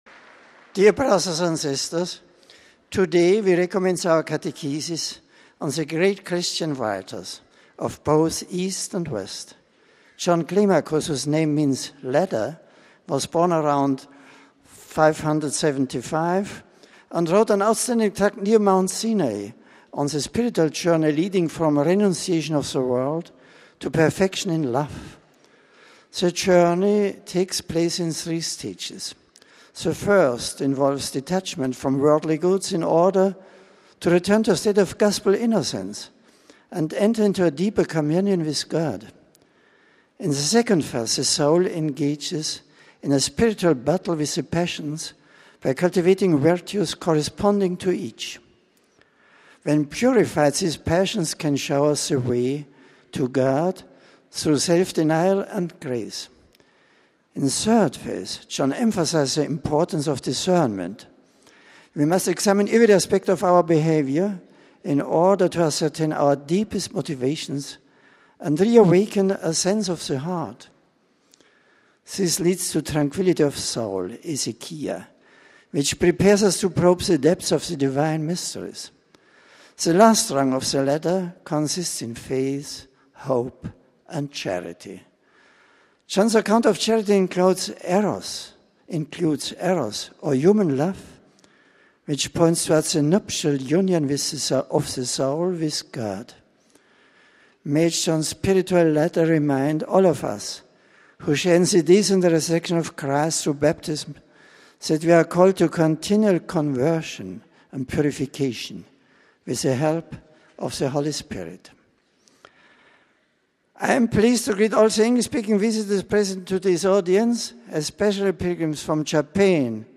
The General Audience of Feb. 11 was held in the Vatican’s Paul VI audience hall. It began with a scripture reading in various languages. An aide greeted the Pope on behalf of the English-speaking pilgrims, presenting the various groups to him. Pope Benedict then delivered a reflection in English.